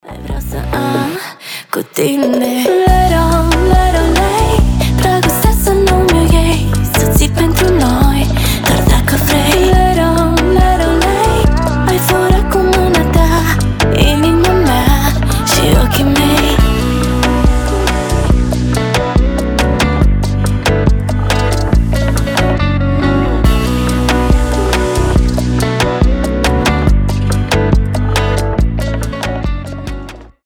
Лирика
Женский голос
Поп